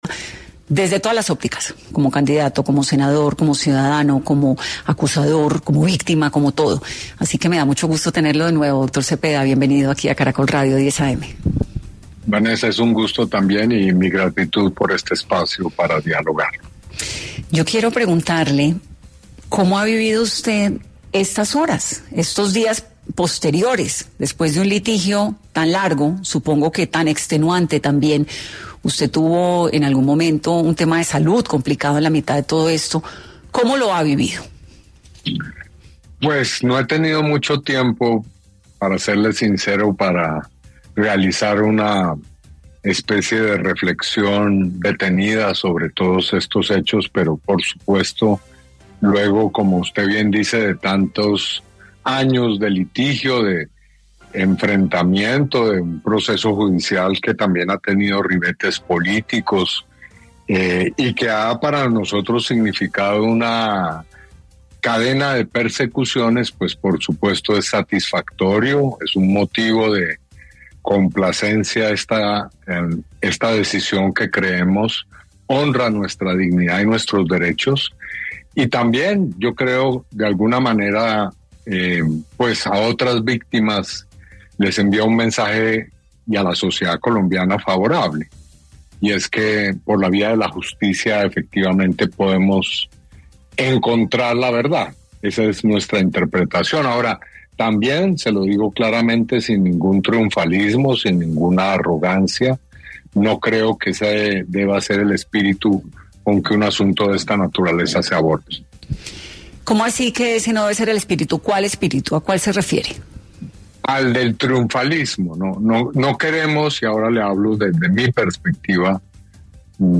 En su paso por 10AM de Caracol Radio, estuvo el senador Iván Cepeda, quién se habló sobre lo sucedido con el caso del expresidente Uribe.